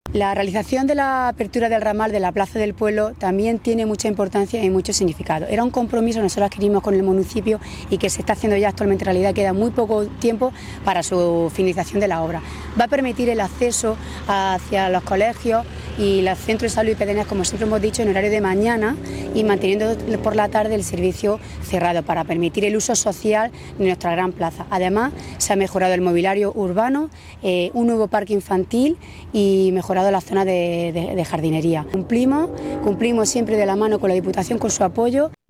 19-03_dipu_obras_albox_plaza_pueblo_ramal_alcaldesa.mp3